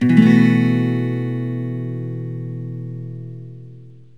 B7sus4.mp3